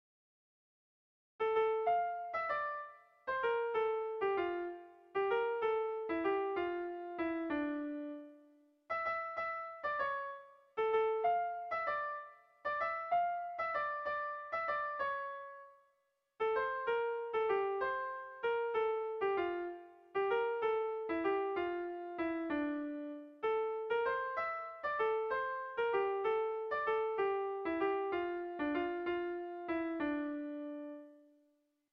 Kontakizunezkoa
Zortziko handia (hg) / Lau puntuko handia (ip)
ABDE